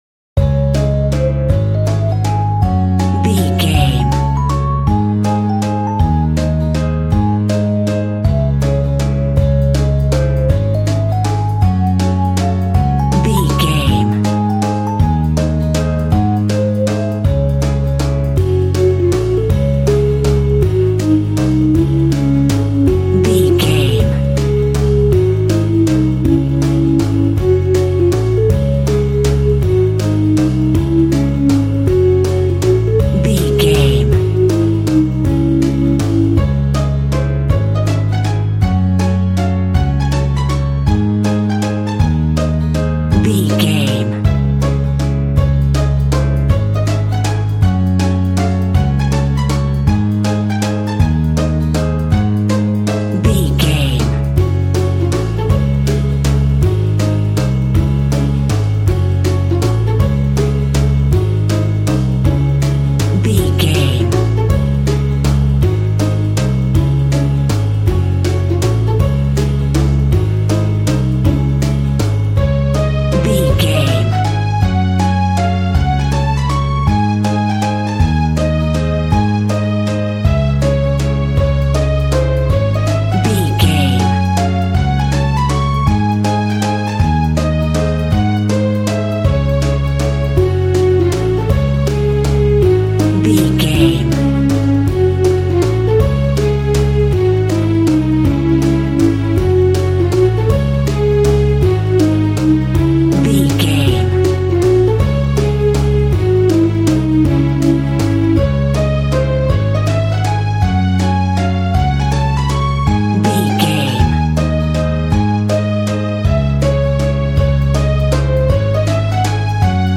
Aeolian/Minor
C#
instrumentals
childlike
cute
happy
kids piano